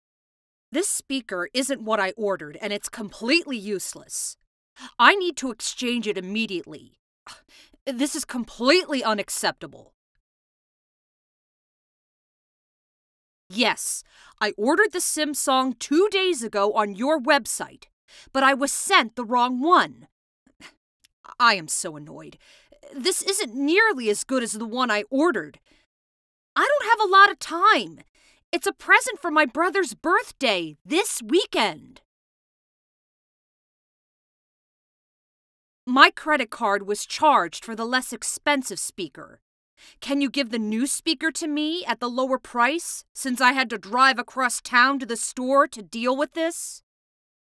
Female
English (North American)
Adult (30-50)
All our voice actors have professional broadcast quality recording studios.
0130Angry_Customer.mp3